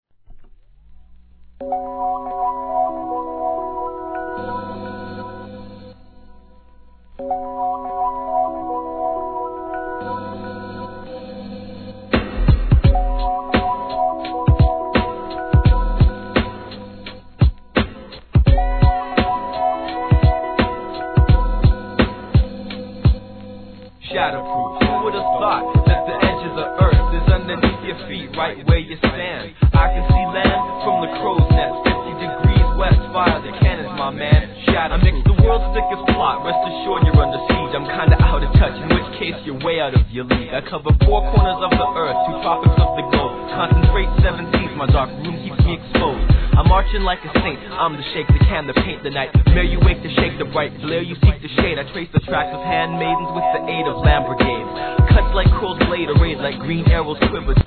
HIP HOP/R&B
浮遊感あるDOPEトラックでのMICリレー!!